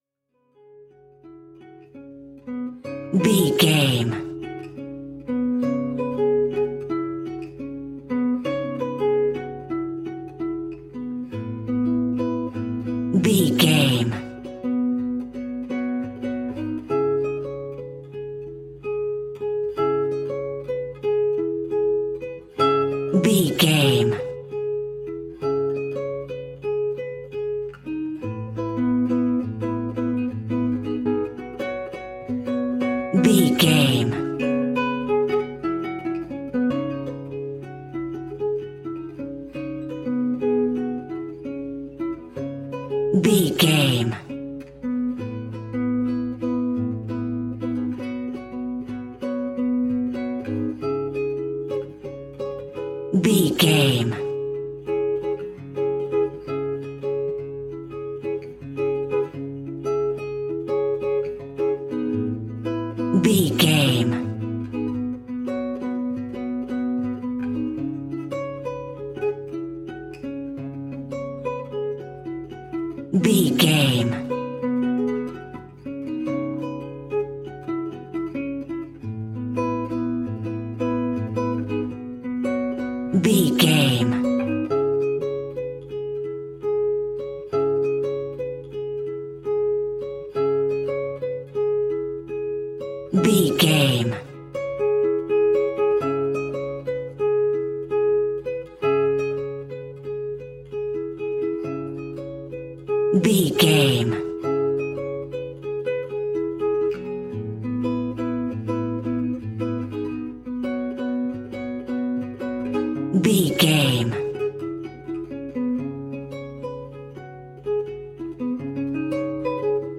Aeolian/Minor
C#
maracas
percussion spanish guitar